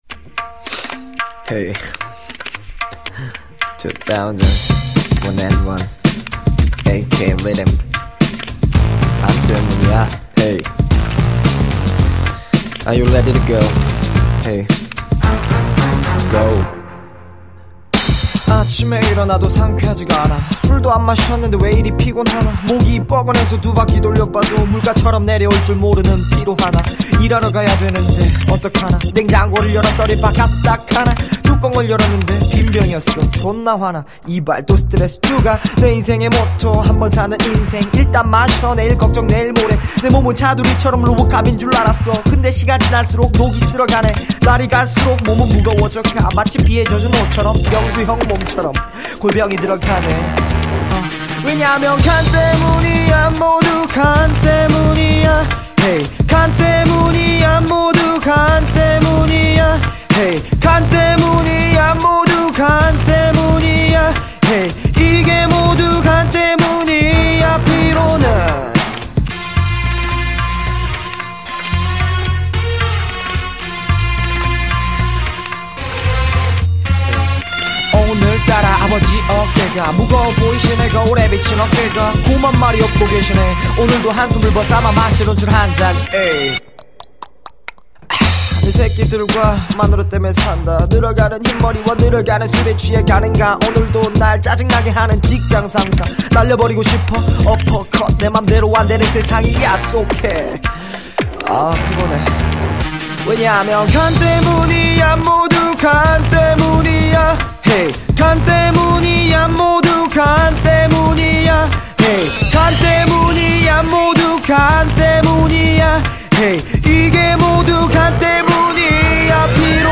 공연을 한다는 가정하에 만들어진 노래입니다.^.^